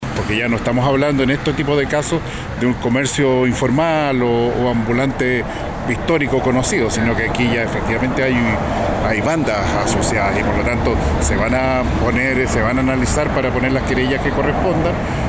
Por su parte, el delegado Presidencial Regional, Yanino Riquelme, hizo un llamado a que la gente no siga comprando al comercio ilegal, ya que esto fomenta su persistencia.